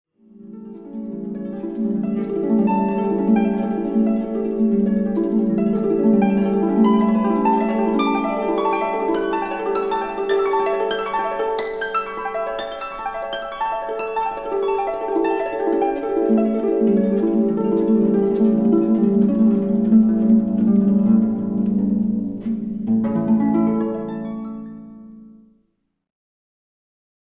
groep5_les1-5-2_geluiddieren3.mp3